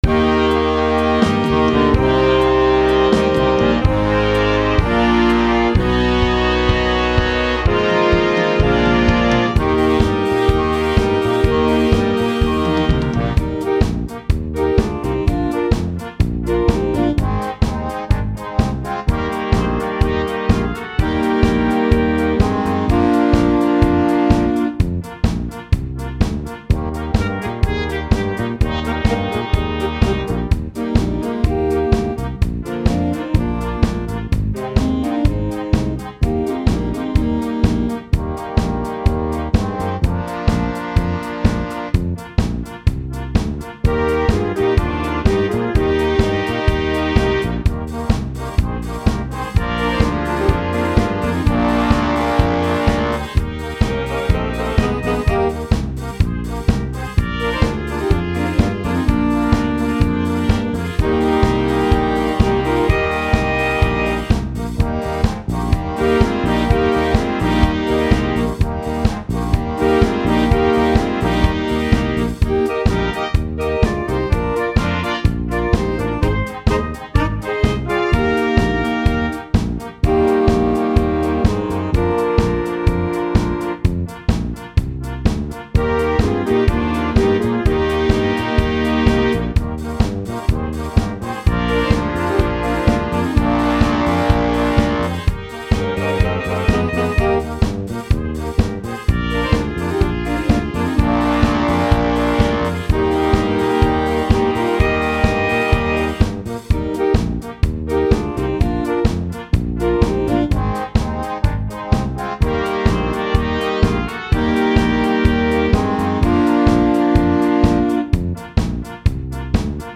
Ponúkam kvalitné aranžmány vhodné pre dychové orchestre.